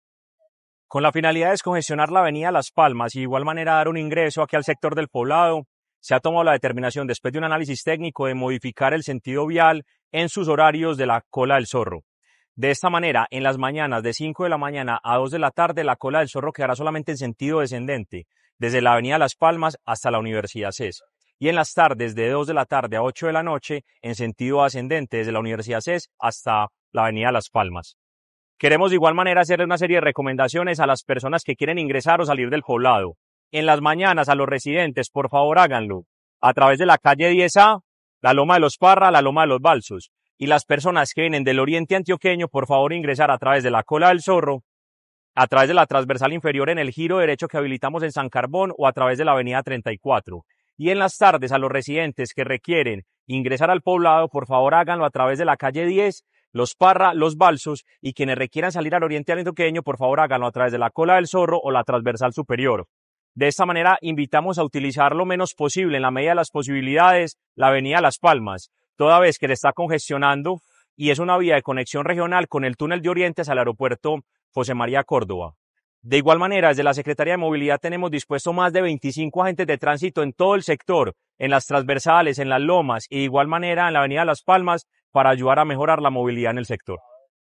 Declaraciones-del-secretario-de-Movilidad-Mateo-Gonzalez-Benitez.mp3